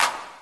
Clap